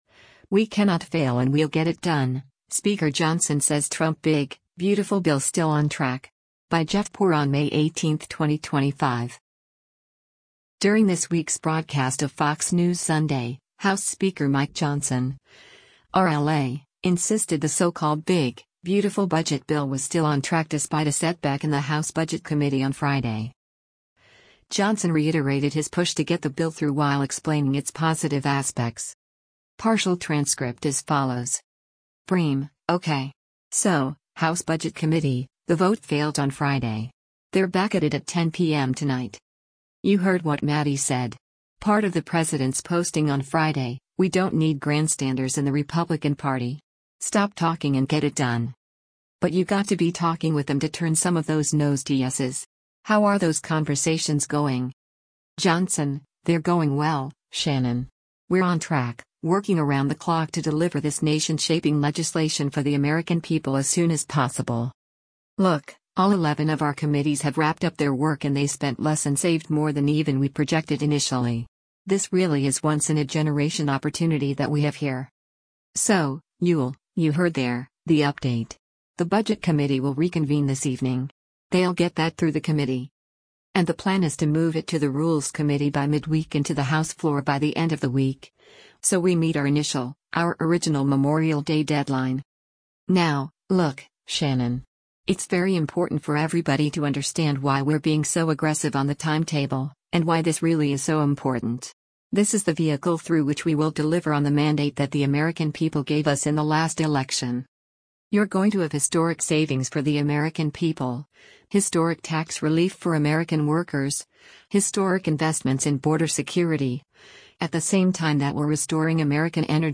During this week’s broadcast of “Fox News Sunday,” House Speaker Mike Johnson (R-LA) insisted the so-called “big, beautiful” budget bill was still on track despite a setback in the House Budget Committee on Friday.